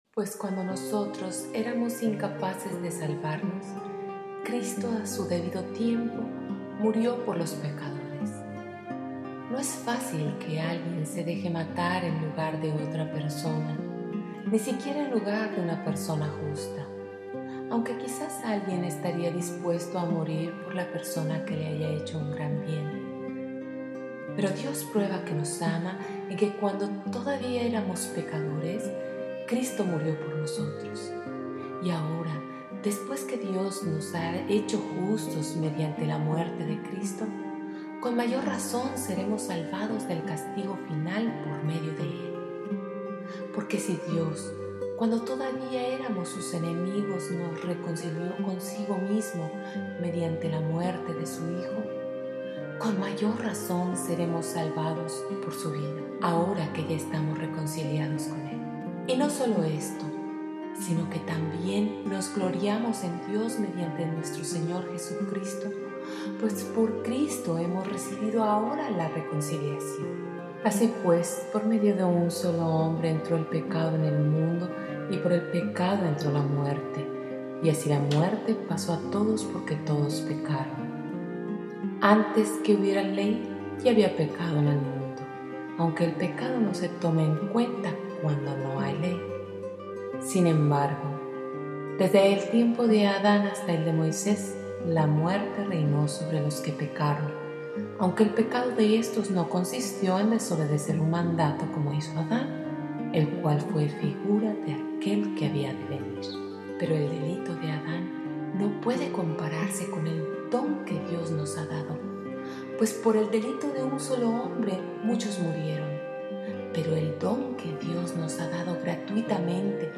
LECTURA PARA MEDITAR